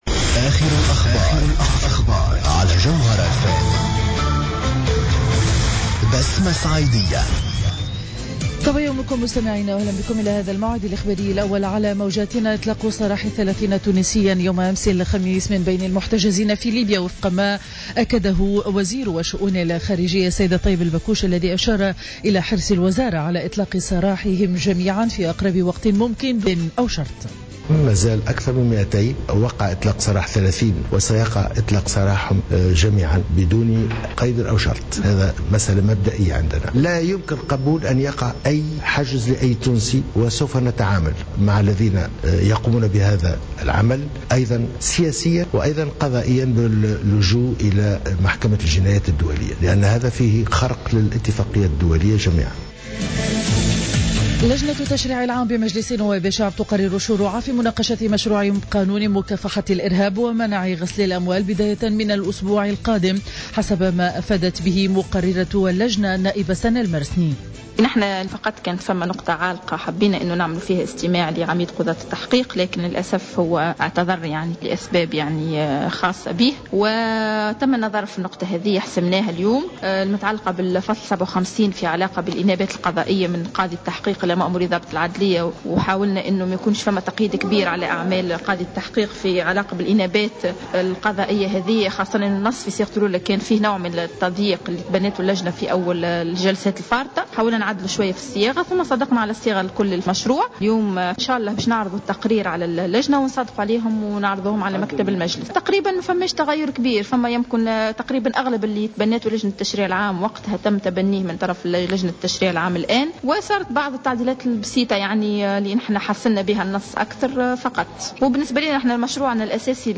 نشرة أخبار السابعة صباحا ليوم الجمعة 29 ماي 2015